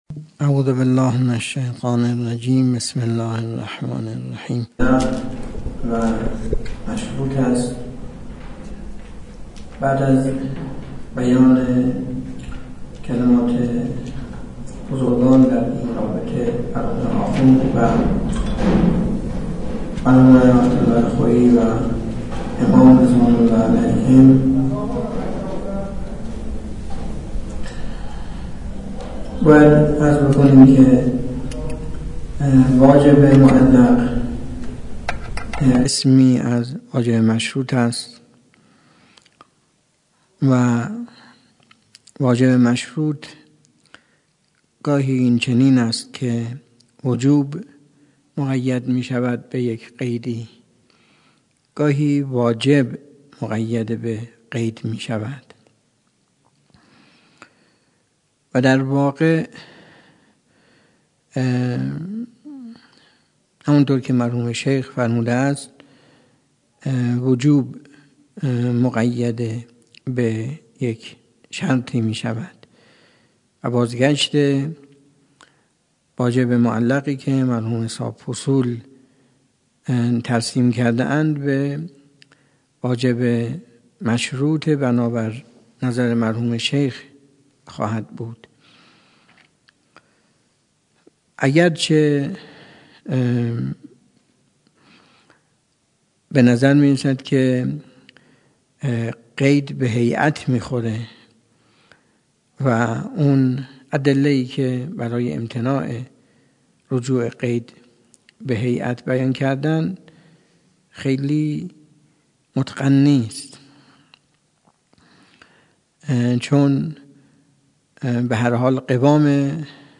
درس خارج اصول
سخنرانی